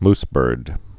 (msbûrd)